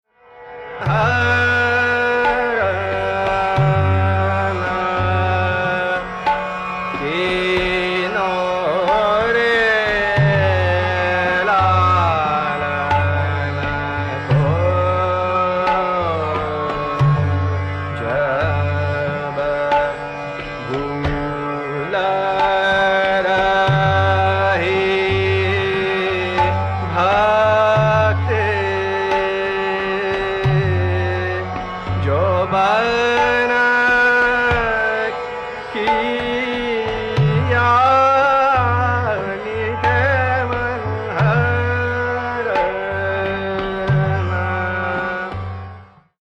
Basanti Kanada | SrGmMPDnNS | Hindustani Raga Index | 365+ Ragas
S-r-G-mM-P-D-nN-S
[refrain, e.g. 1:23] D m G, G GM(d)M\G, (M)G rS, (S)G rS, S(NR)S, Sm, m m\G, P m\G, G(m)D DP, DPDND, PDnDP…
• Tanpura: Sa–Pa